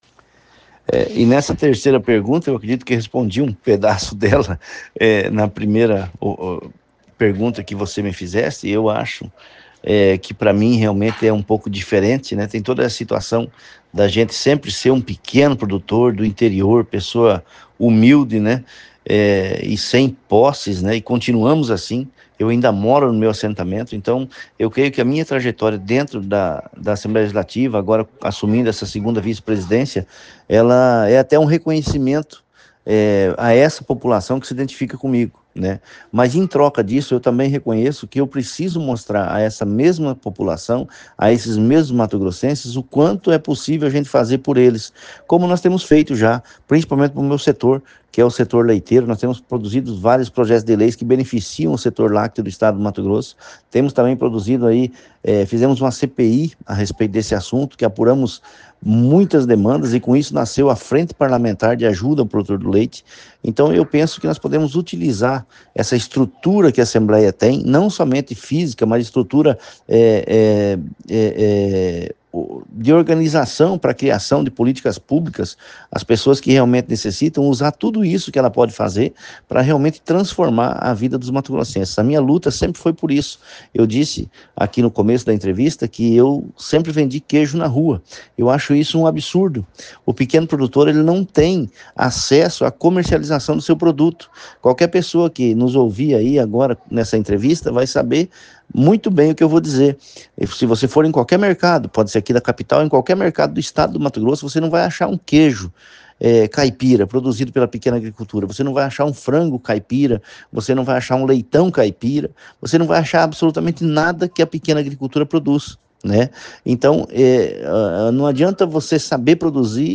OUÇA NA ÍNTEGRA A ENTREVISTA QUE O SITE OPINIÃO FEZ COM O DEPUTADO GILBERTO CATTANI